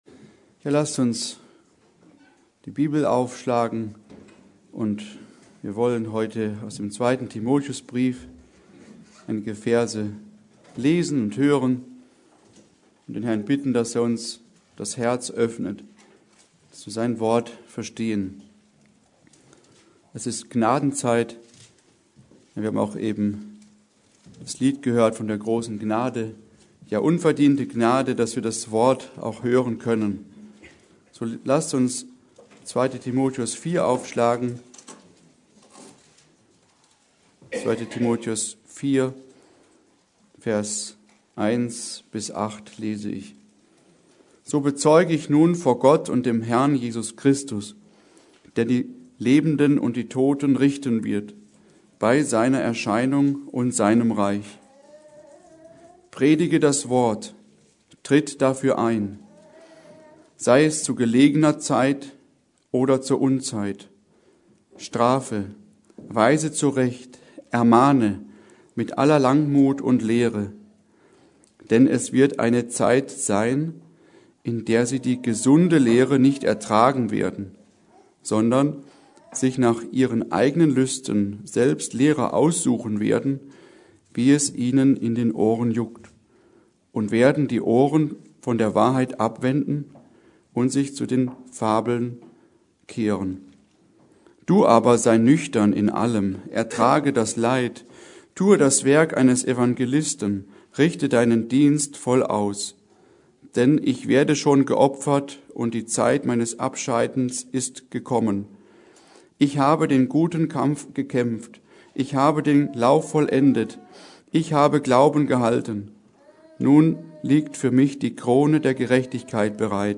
Predigt: Darum wacht!